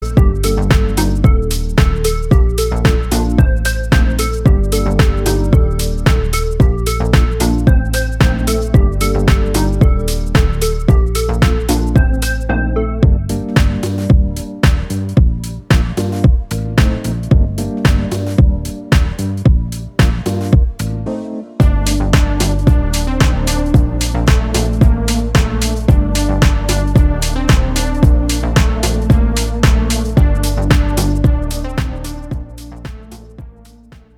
• Качество: 320, Stereo
deep house
атмосферные
спокойные
без слов
красивая мелодия
Electronica
Downtempo